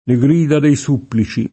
supplice [ S2 ppli © e ]